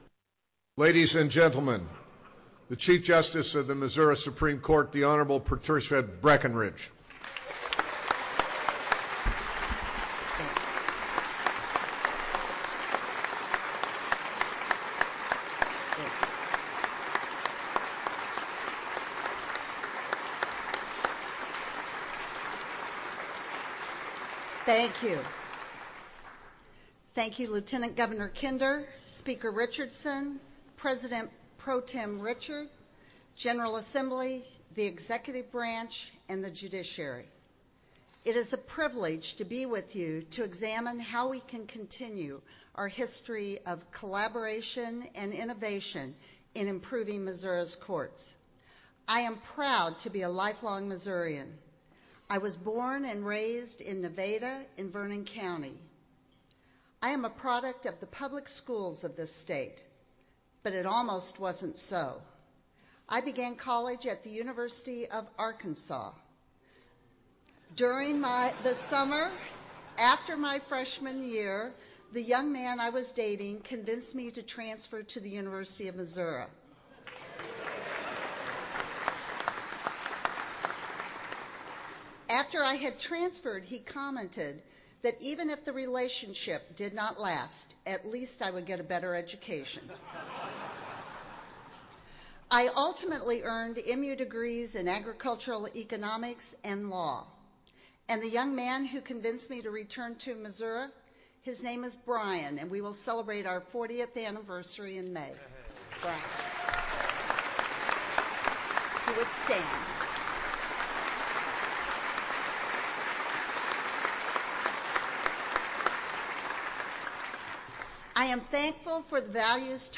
Patricia Breckenridge, chief justice of the Supreme Court of Missouri, delivered the following State of the Judiciary address Wednesday morning, January 27, 2016, during a joint session of the Missouri General Assembly in Jefferson City, Mo.